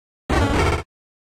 Archivo:Grito de Munchlax.ogg
== Licencia == {{Archivo de audio}} Categoría:Gritos de Pokémon de la cuarta generación